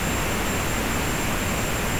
turbo.wav